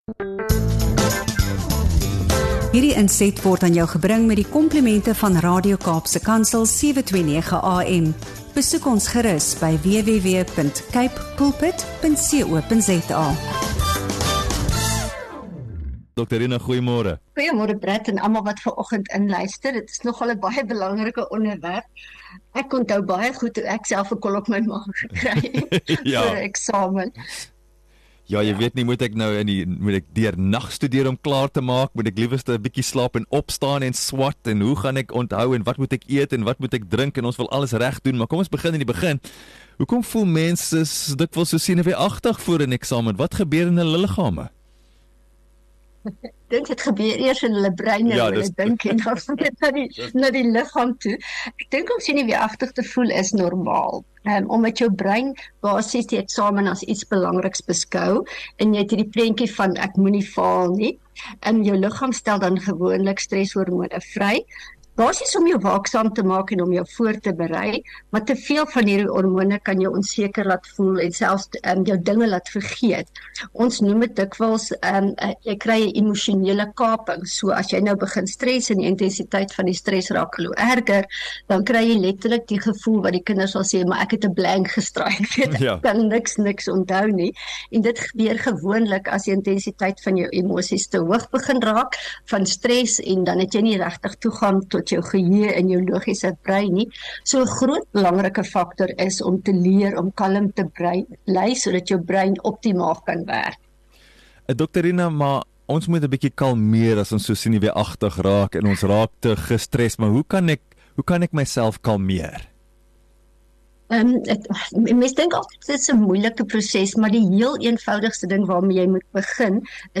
Sy verduidelik hoe asemhaling, positiewe denke, voorbereiding en genoeg slaap kan help om kalm te bly en beter te presteer. Hierdie gesprek, uitgesaai op Radio Kaapse Kansel 729 AM, bied waardevolle wenke vir leerders, ouers en onderwysers oor die hantering van stres en die bevordering van selfvertroue tydens eksamens.